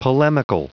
Prononciation du mot polemical en anglais (fichier audio)
Prononciation du mot : polemical